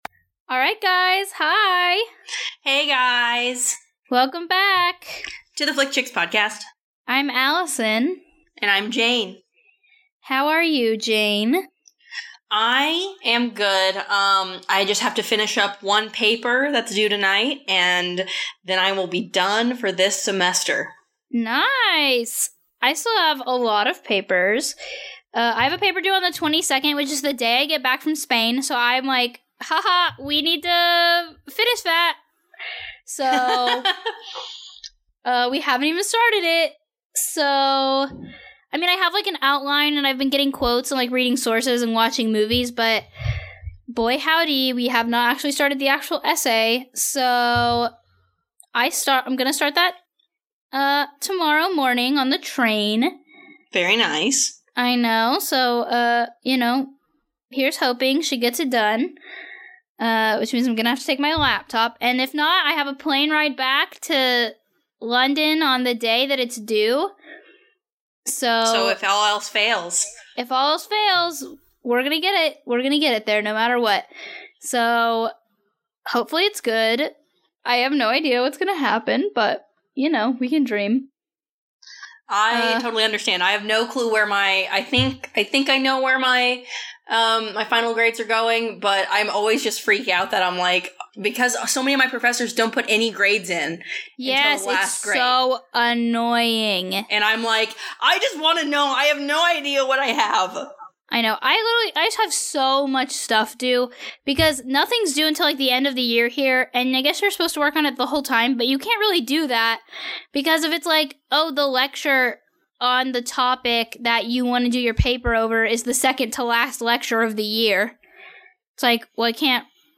Get in the holiday spirit as the girls review this classic action flick!